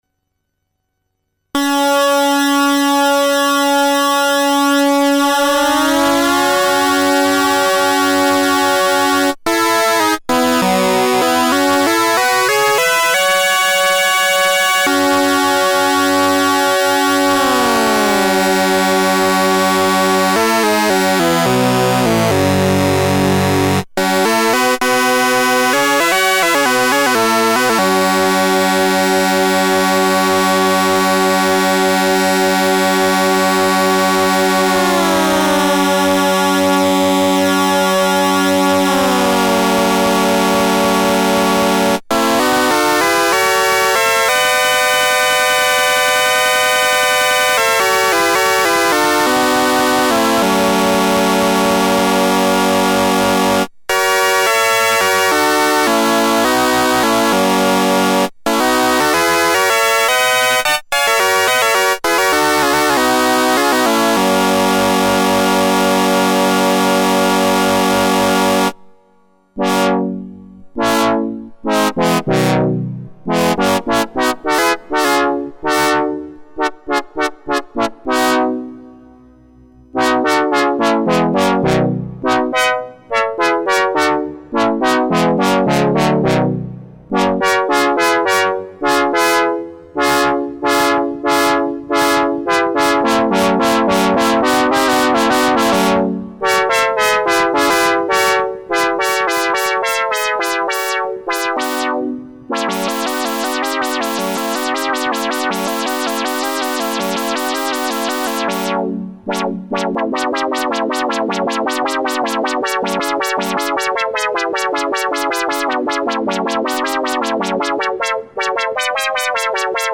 at first 3 oscillators in 4' register switched with pulse waveform than every oscillator's tune slider tweaking. Open filter at first and than you can hear filtering signal.
tunetweaking.mp3